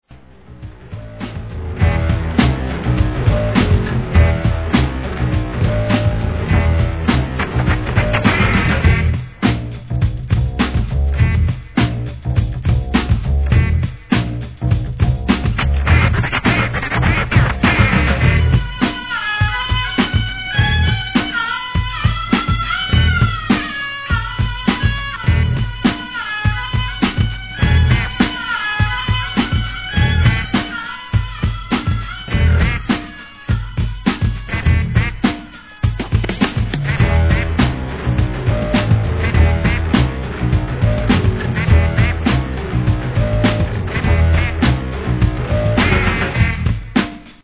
HIP HOP/R&B
ドラム、ベースと共に生演奏で作り出すGROOVE感に心が躍ります♪ No. タイトル アーティスト 試聴 1.